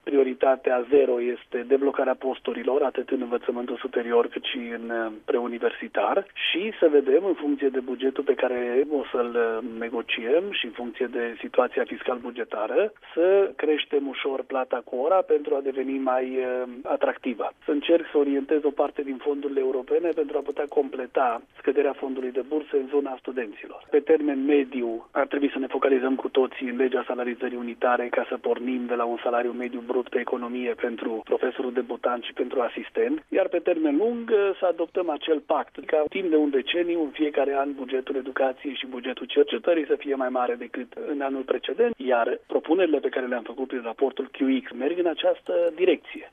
Daniel David a explicat, la Radio România Actualităţi, că îşi doreşte o schimbare de paradigmă în ceea ce priveşte sistemul educaţional şi a enumerat care ar fi priorităţile: